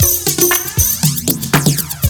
DRUMFILL08-R.wav